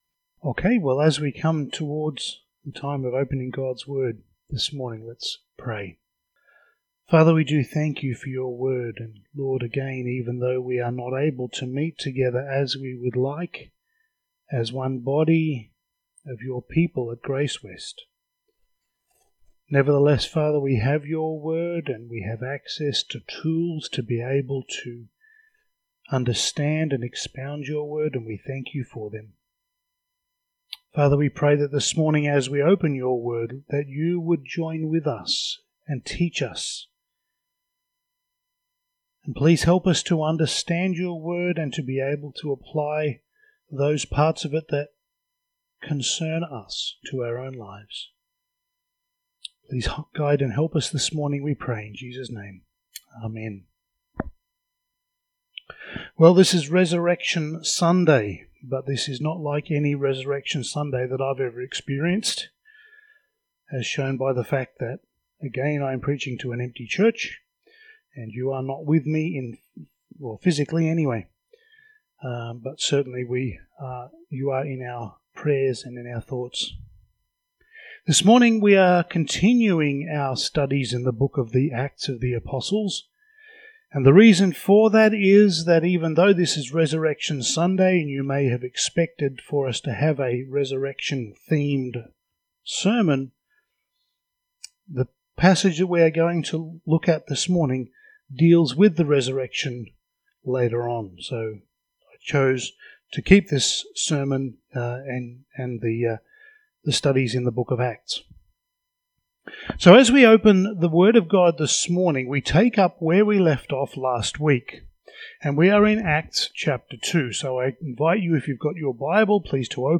Passage: Acts 2:14-24 Service Type: Sunday Morning